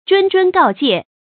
谆谆告诫 zhūn zhūn gào jiè
谆谆告诫发音
成语正音谆，不能读作“chún”。